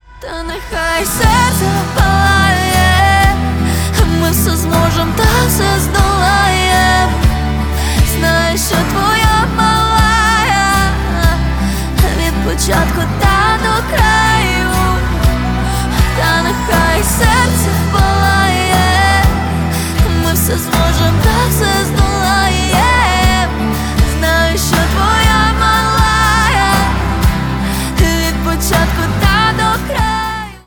Українська музика на дзвінок 2026